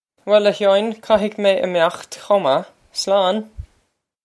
Pronunciation for how to say
Well, ah Hyaw-in, kah-hig may im-okht khoh mah. Slawn!
This is an approximate phonetic pronunciation of the phrase.